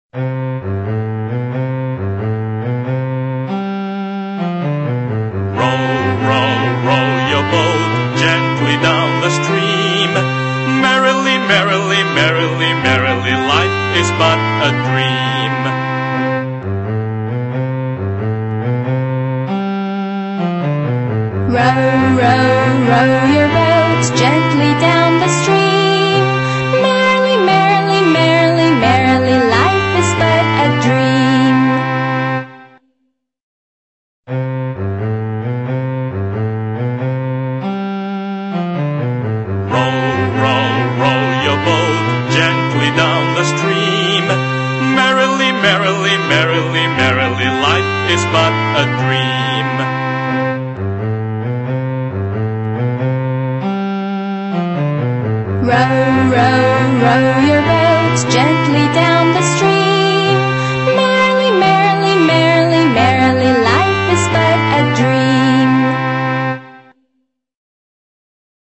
在线英语听力室英语儿歌274首 第184期:Row,Row,Row,Your Boat(2)的听力文件下载,收录了274首发音地道纯正，音乐节奏活泼动人的英文儿歌，从小培养对英语的爱好，为以后萌娃学习更多的英语知识，打下坚实的基础。